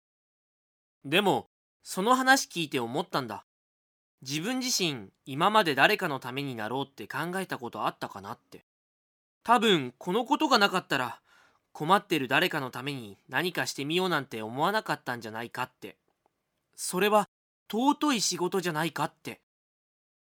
日本語 男性